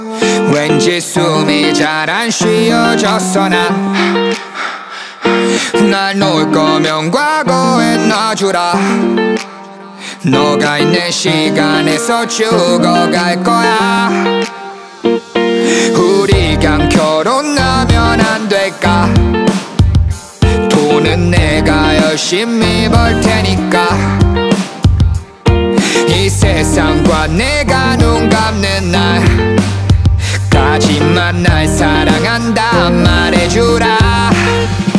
Жанр: Рэп и хип-хоп
# Korean Hip-Hop